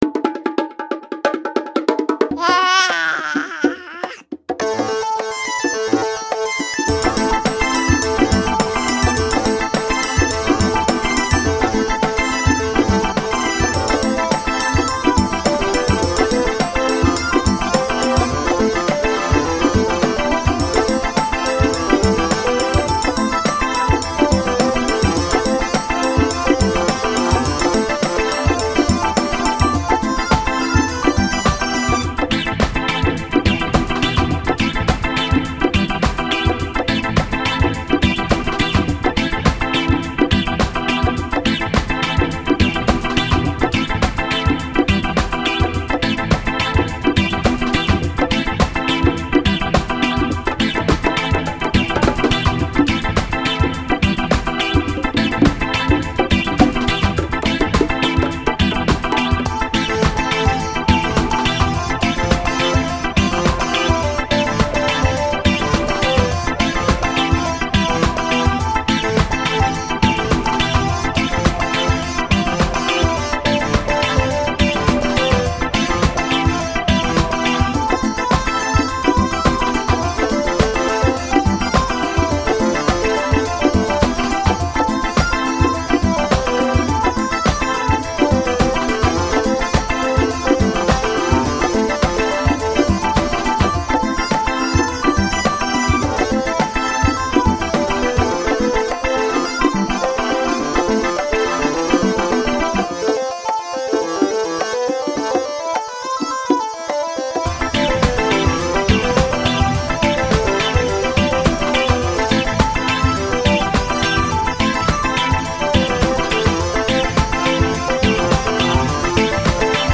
Produção sonora vinheteira, com notas distintas e com ênfase na redundância cíclica.